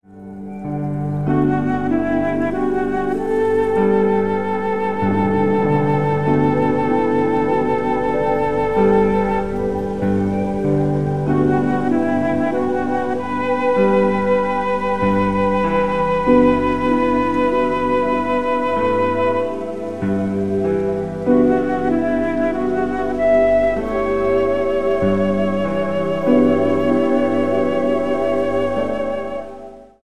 48 BPM
Pad with low piano chords morphsinto a flute solo over same.